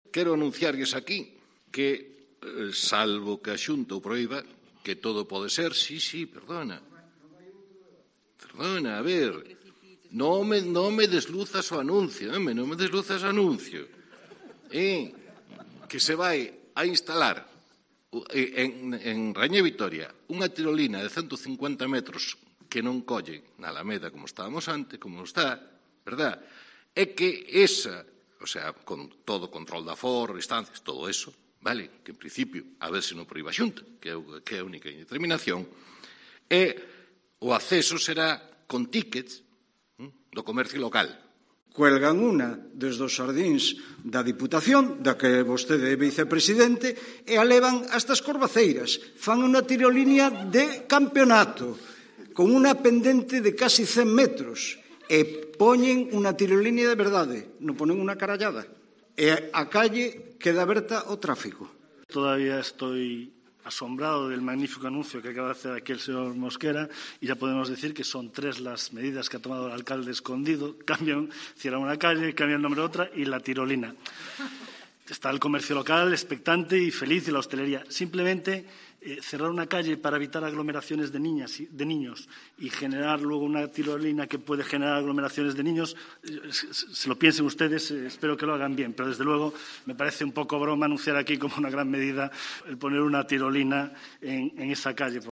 Anuncio de la instalación de una tirolina y reacciones en el pleno de Pontevedra